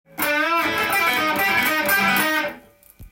譜面通り弾いてみました